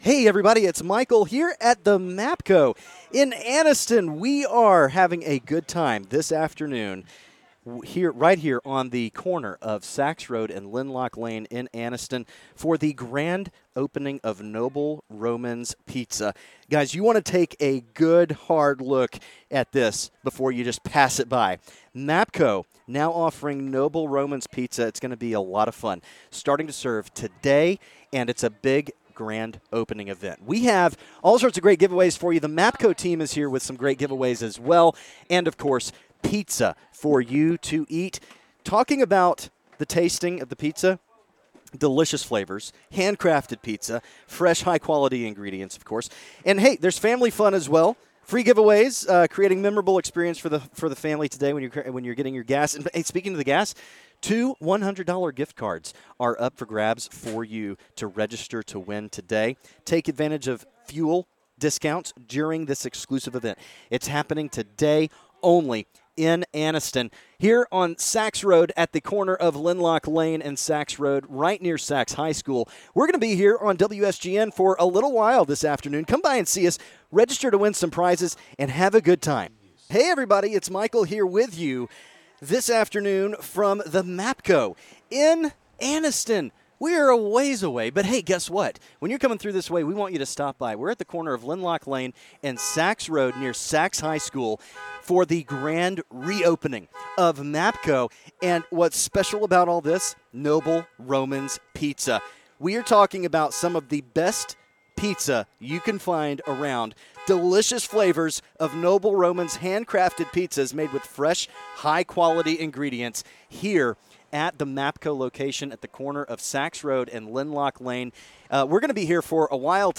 [ON LOCATION]